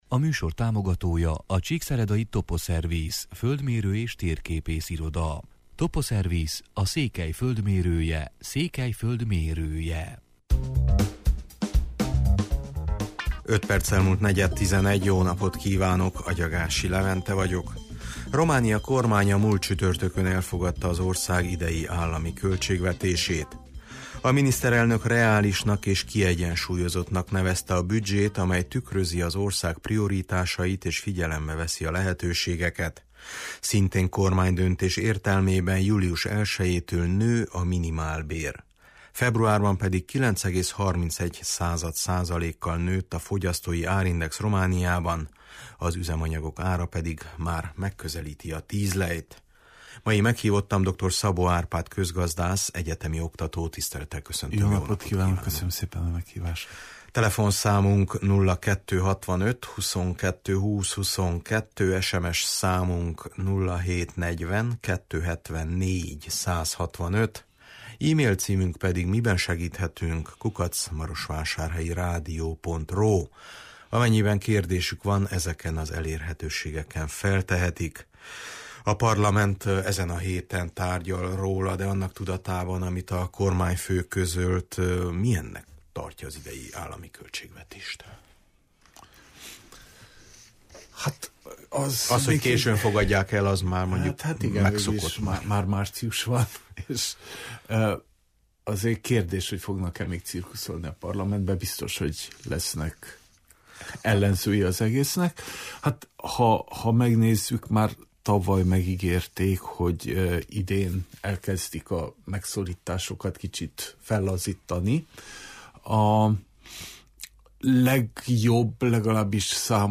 közgazdász, egyetemi oktató